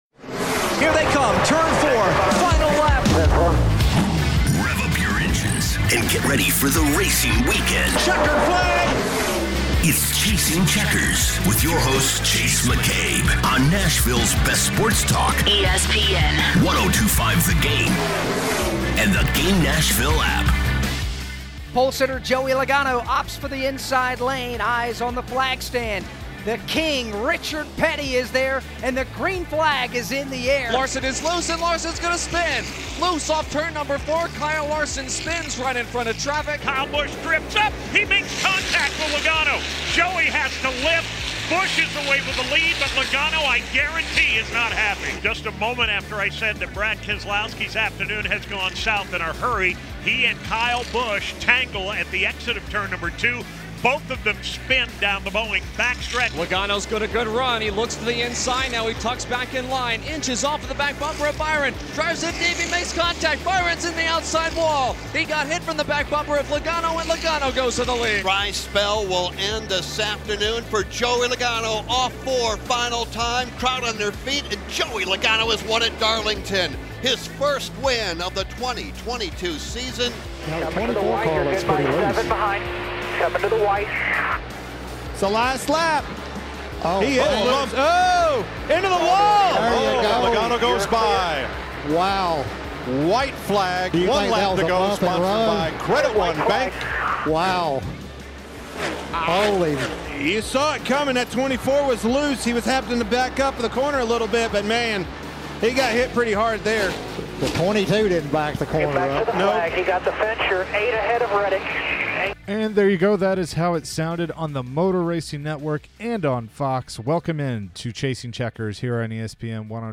chats with driver Chris Buescher